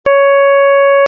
Ἡ διάρκεια τοῦ κάθε φθόγγου εἶναι 1 δευτερόλεπτο.
Πα = 247.5Hz
Κλίμακα Πα-Πα'
Οἱ ἤχοι ἔχουν παραχθεῖ μὲ ὑπολογιστὴ μὲ ὑπέρθεση ἀρμονικῶν.